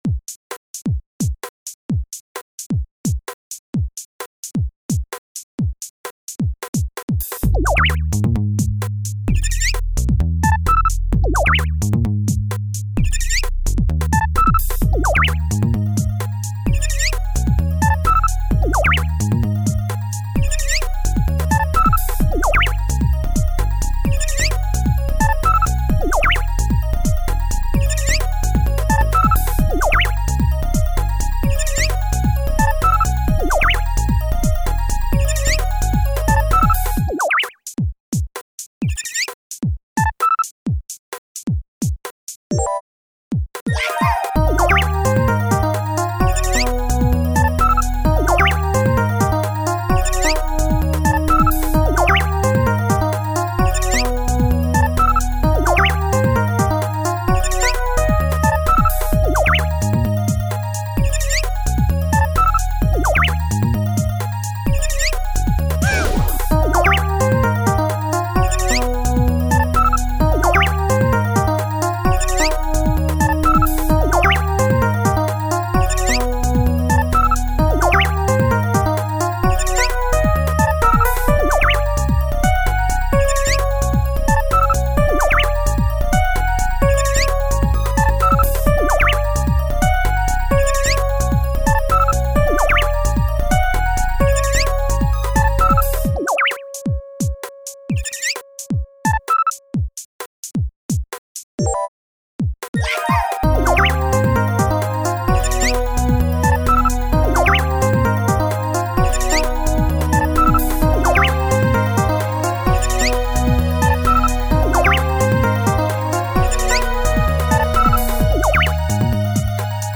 原曲はまったりゆったりムードな曲をなんか適当にいぢってみたw
独自のドラムキットを使ってますが、まぁ、基本はbeatでいいんじゃない？w